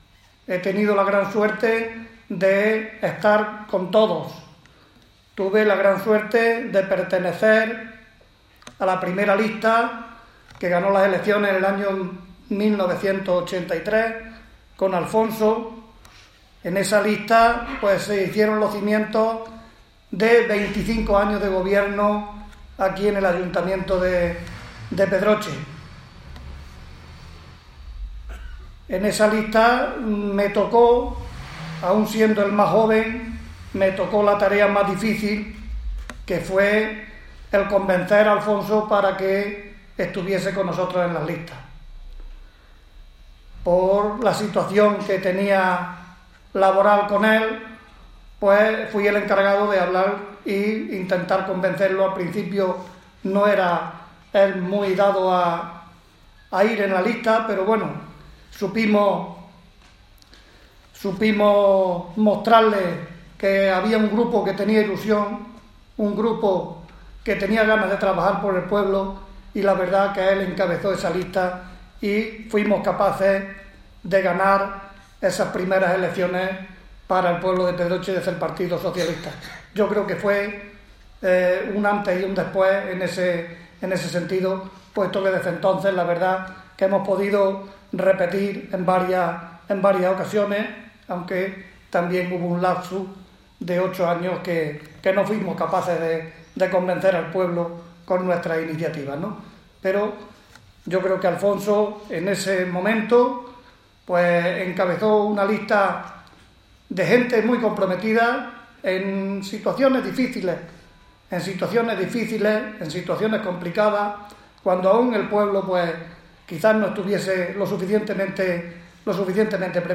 Ayer tuvo lugar en Pedroche un acto de recuerdo, organizado por la agrupación socialista de la localidad, en homenaje a dos ex-alcaldes y una concejala en activo, fallecidos hace un año.
A continuación habló Santiago Ruiz, alcalde: